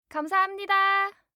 알림음 8_감사합니다2-여자.mp3